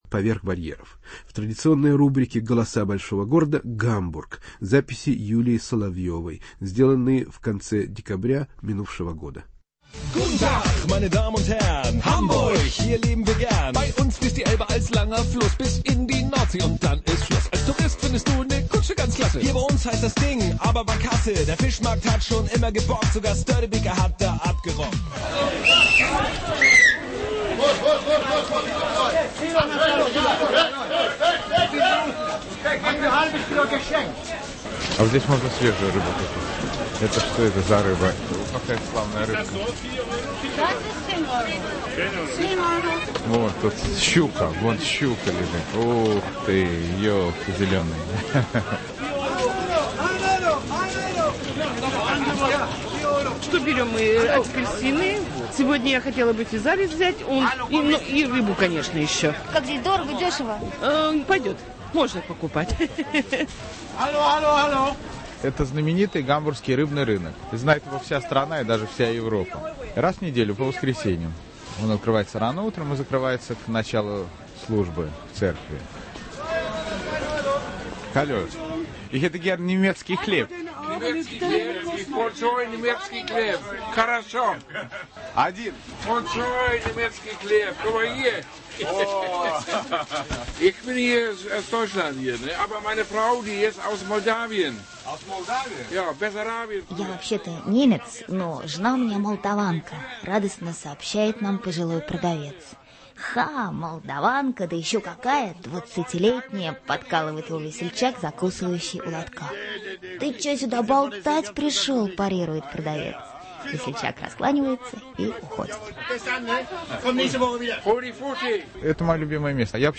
"Голоса большого города": акустический портрет Гамбурга